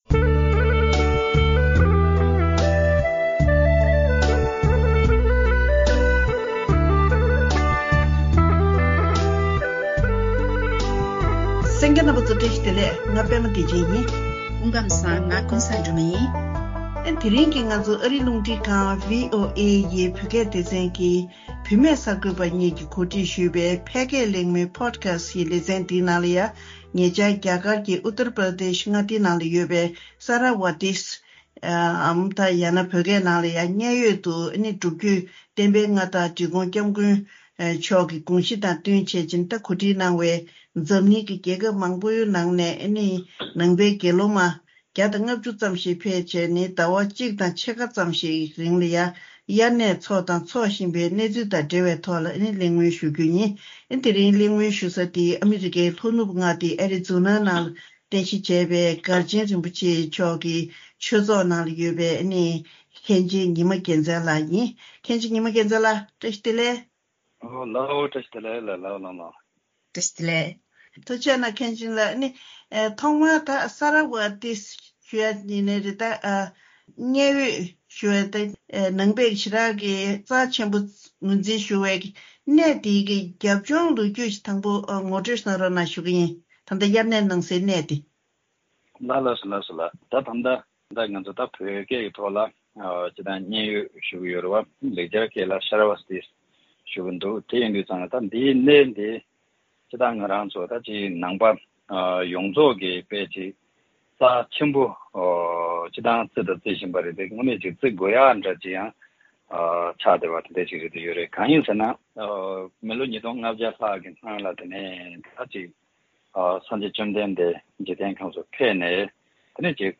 ༄༅། སྔོན་བདག་ཅག་གི་སྟོན་པ་སངས་རྒྱས་ཤཱཀྱ་ཐུབ་པ་དབྱར་གནས་ཐེངས་ཉི་ཤུ་རྩ་ལྔ་བཞུགས་གནས་མཛད་ས་རྒྱ་གར་གྱི་གནས་ཆེན་མཉན་ཡོད་ཀྱི་ལོ་རྒྱུས་དང་། དེ་བཞིན་འབྲི་གུང་༧སྐྱབས་མགོན་མཆོག་གིས་མཐུན་འགྱུར་འོག་མཉན་ཡོད་དུ་དགེ་སློང་མ༡༥༠ཙམ་ཞིག་གིས་དབྱར་གནས་ལ་བཞུག་བཞིན་ཡོད་པའི་སྐོར་ལ་གླེང་མོལ་ཞུས་པ་ཞིག་གསན་རོགས་གནང་།